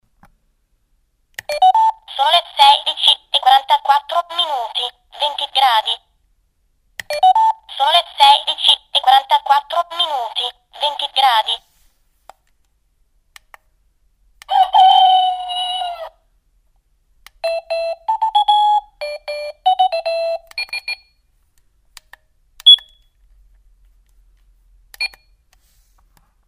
Appendice: sveglia parlante a forma di mela